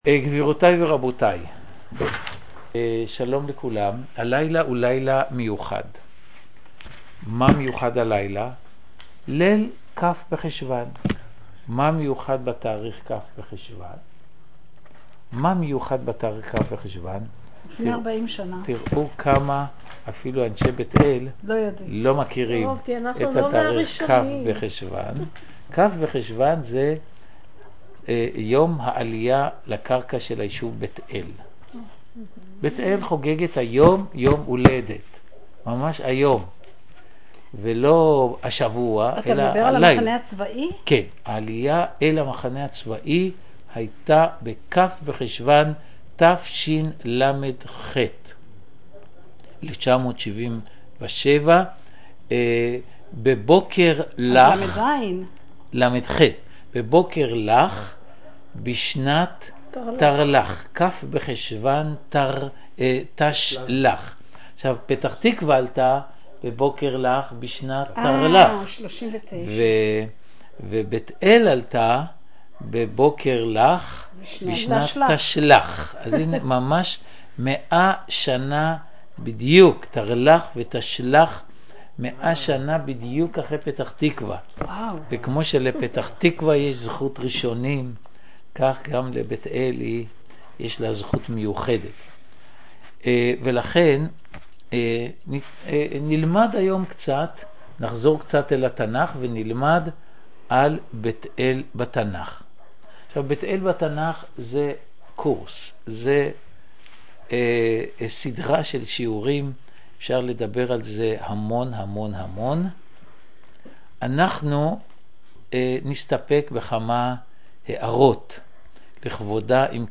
מדרשת נביעה בבית חגלה מציעה יום עיון כל שלושה שבועות.
נושא השיעור: עקדת יצחק.